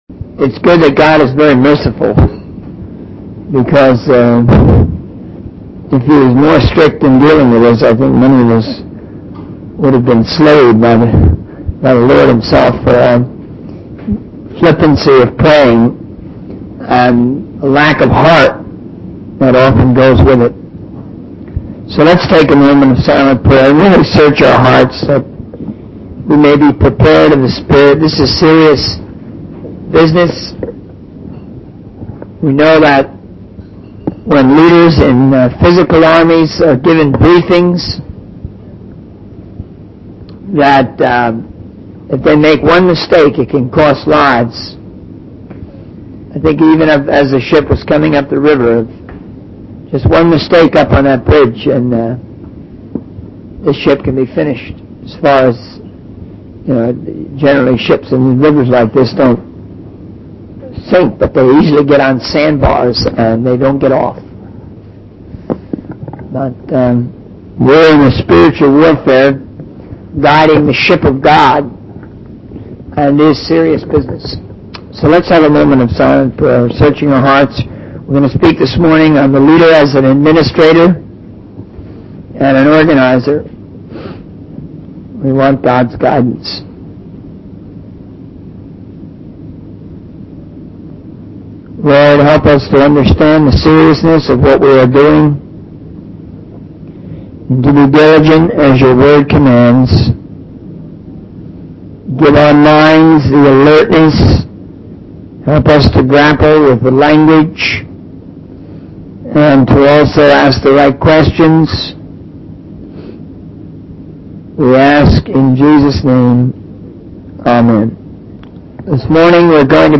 In this sermon, the speaker discusses the importance of effective communication and organization in spreading the word of God. He shares personal anecdotes about his struggles with writing and using chopsticks, highlighting the significance of finding alternative methods to convey messages.